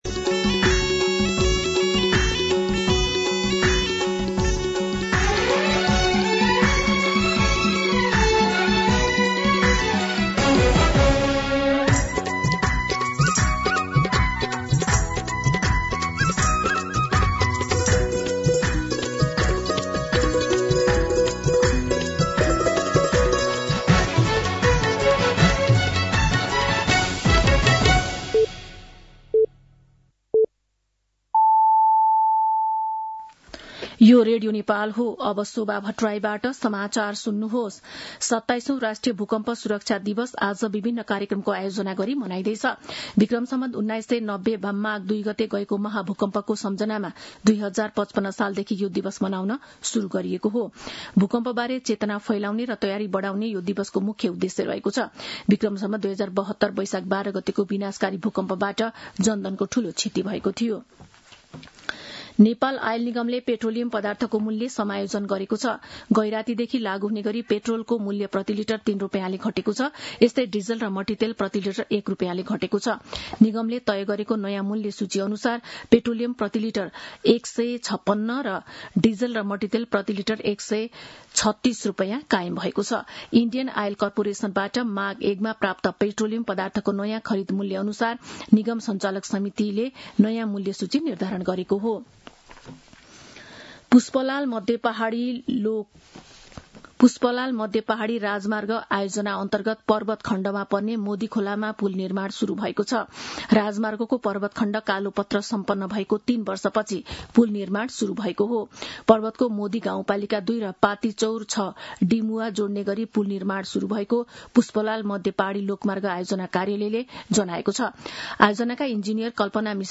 मध्यान्ह १२ बजेको नेपाली समाचार : २ माघ , २०८२